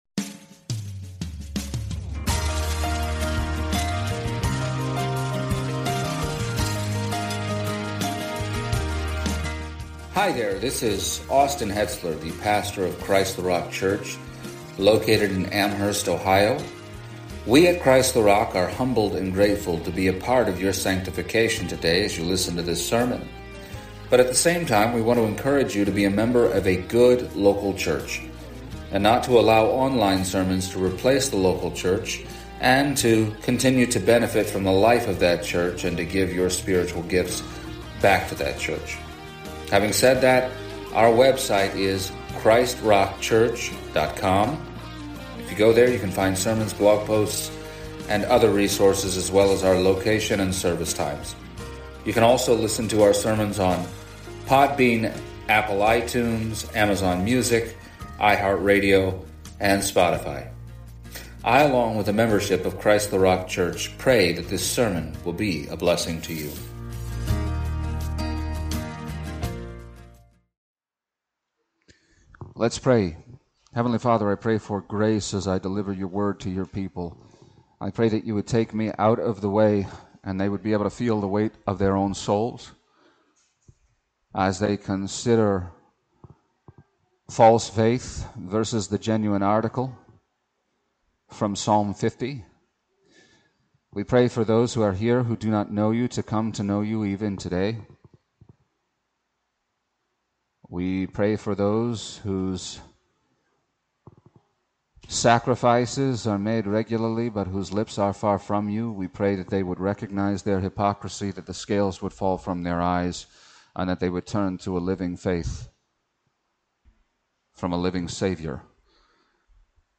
Series: Sunday Service